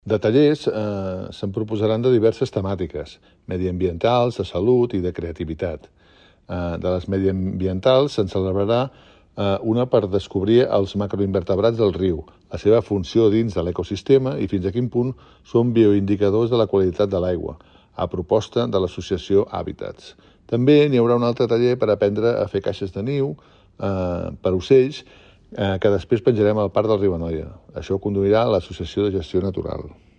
Jordi Amat, regidor de Medi Ambient i Benestar Animal de l'Ajuntament